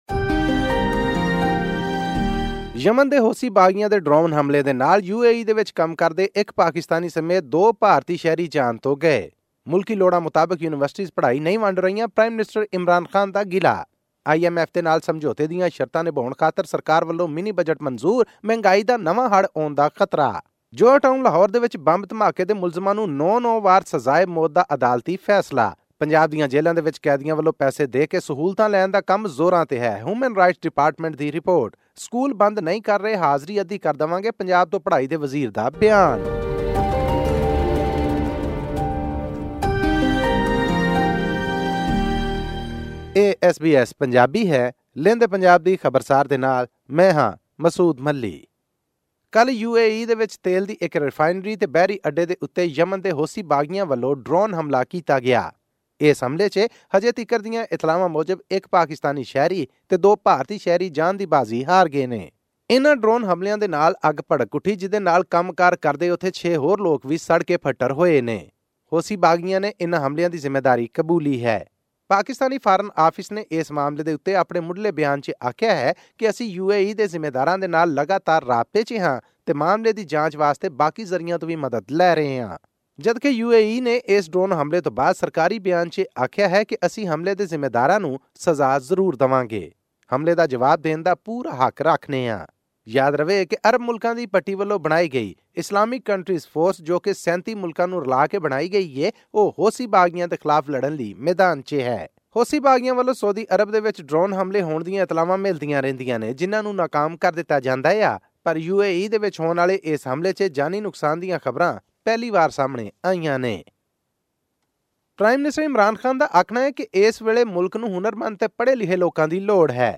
audio report